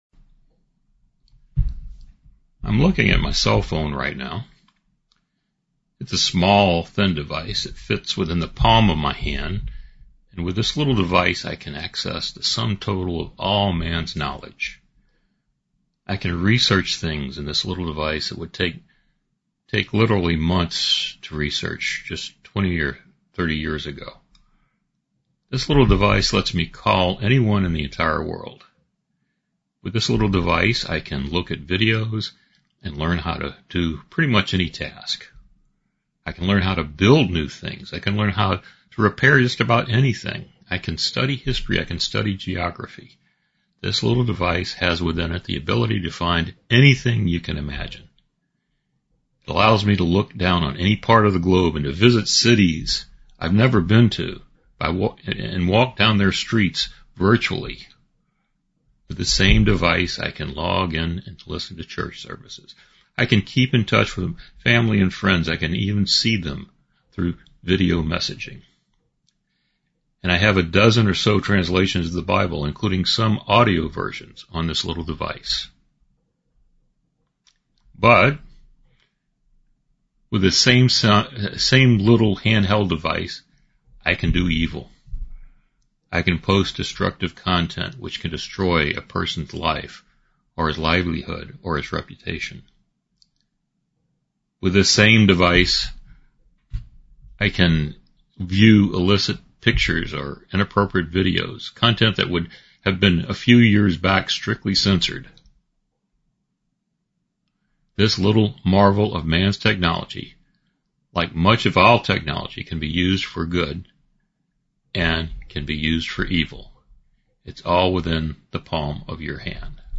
Split Sermon